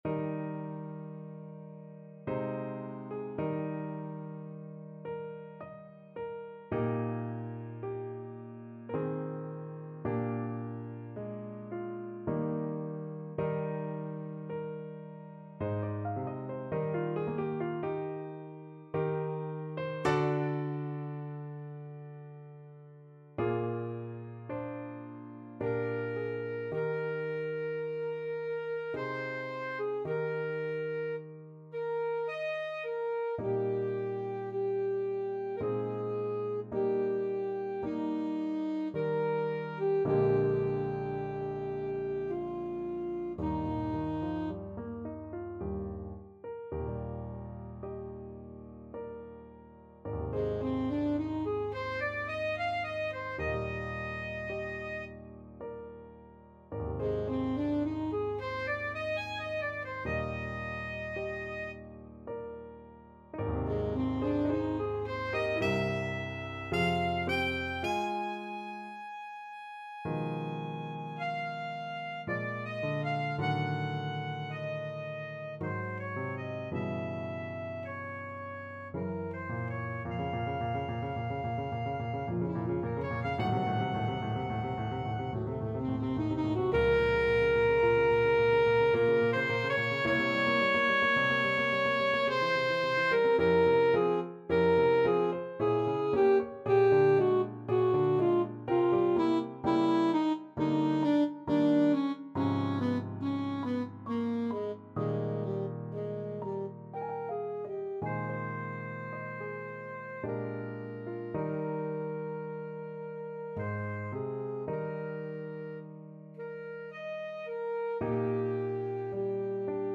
Alto Saxophone version
3/4 (View more 3/4 Music)
Adagio ma non troppo =108
Classical (View more Classical Saxophone Music)